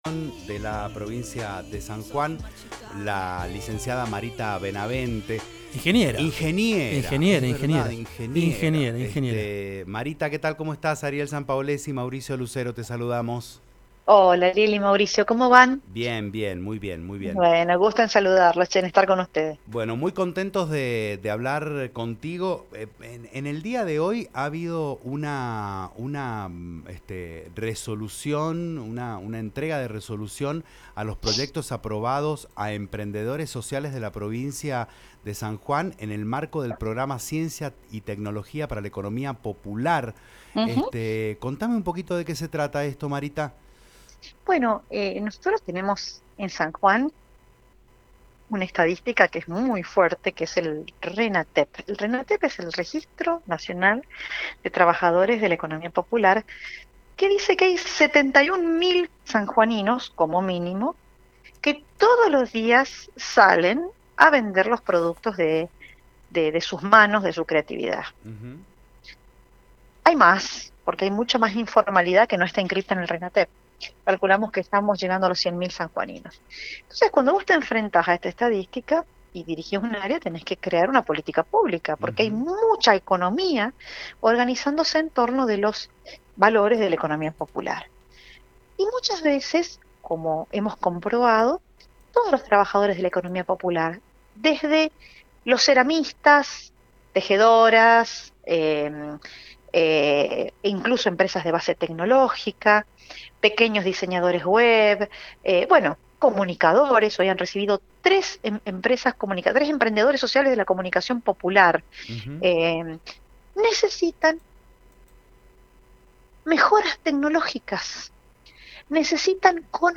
Por la entrega de proyectos del programa ciencia y técnica para la economía popular, la ingeniera y secretaria de Estado de Ciencia y Técnica, diálogó con El Cimbronazo.